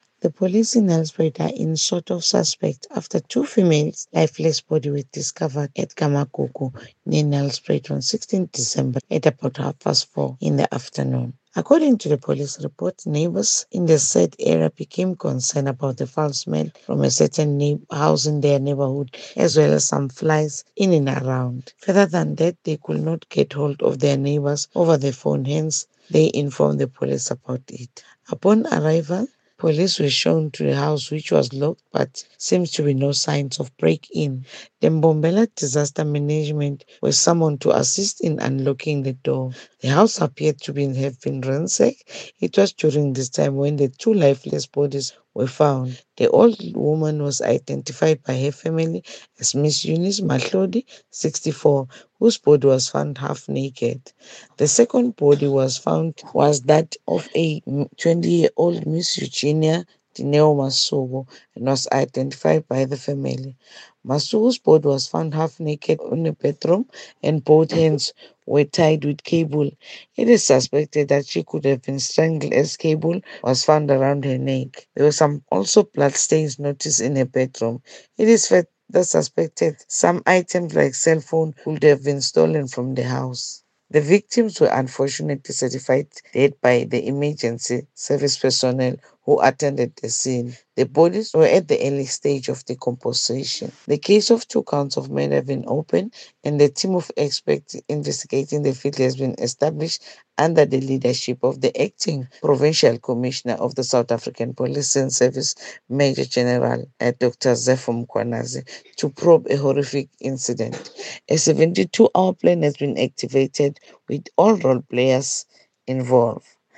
‘n Polisiewoordvoerder